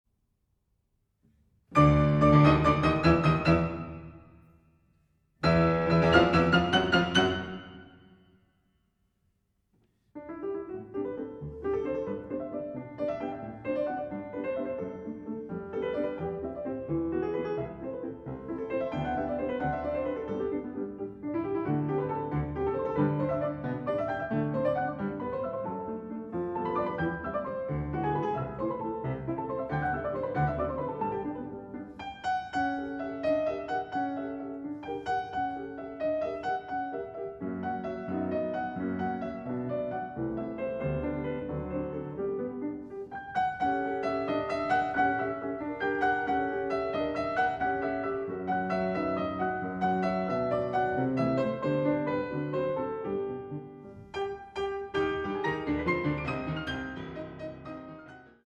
for 2 pianos